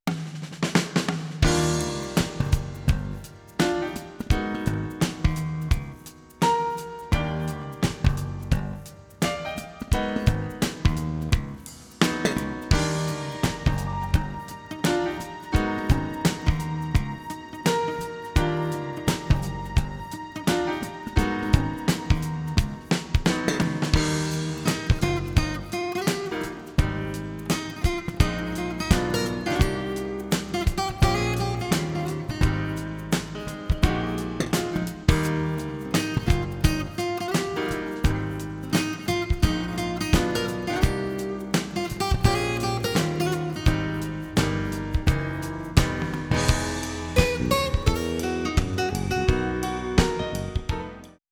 Håll i er, det är mässjazz!
¤ FM-84+15 är båda LFOerna aktiva.